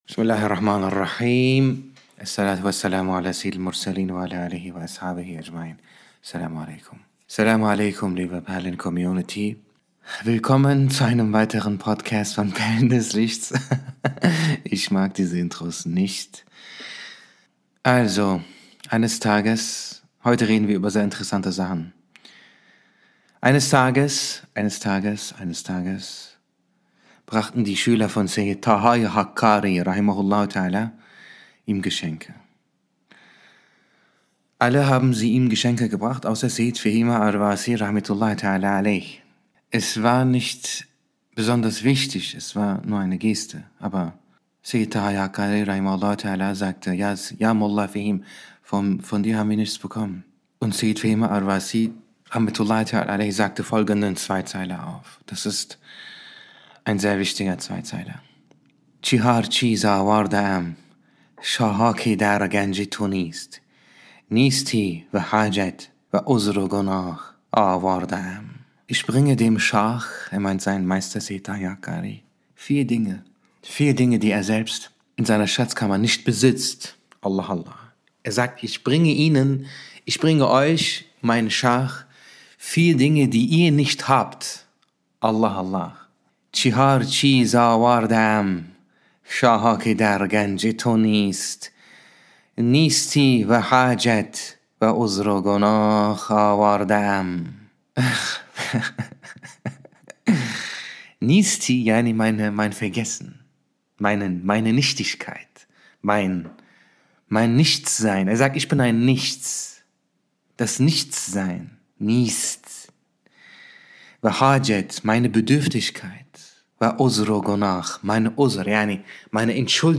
Stil & Sprache: Sanft, poetisch und tief.